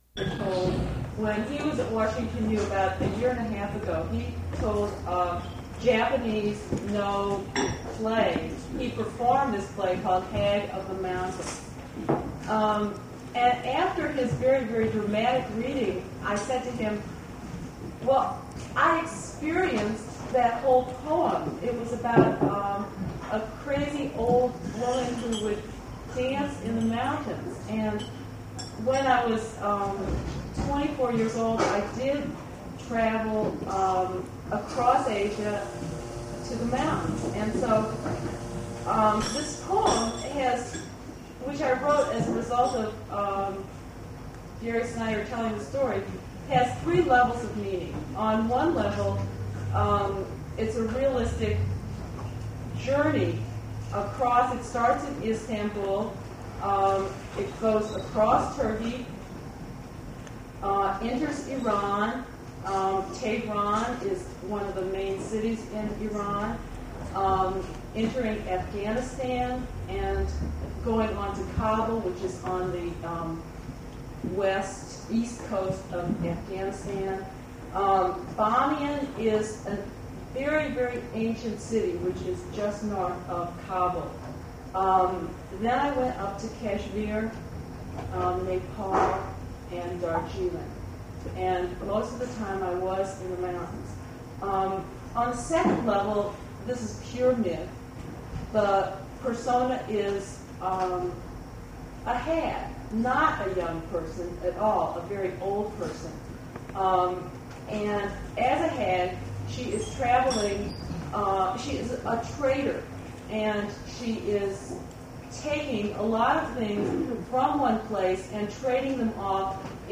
generated from original audio cassette
Not so good recording quality.